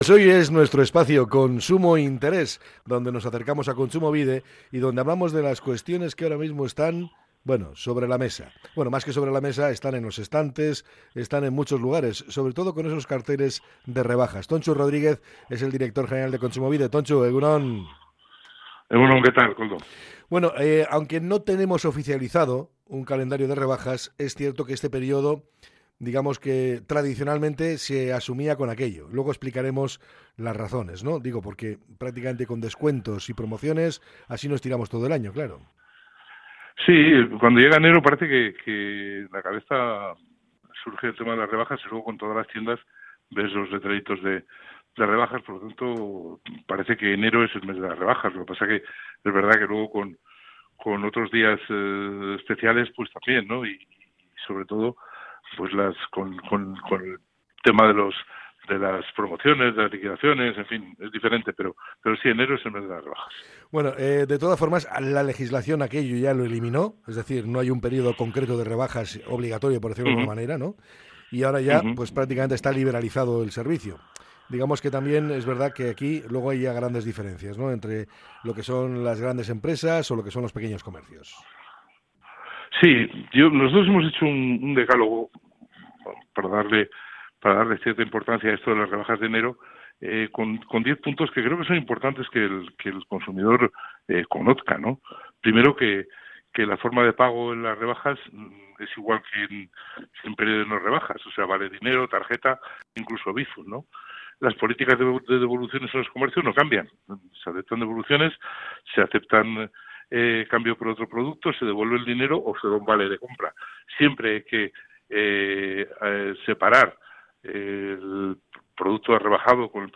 Hablamos con Tontxu Rodríguez, director general de Kontsumobide, para conocer algunos consejos para un consumo responsable.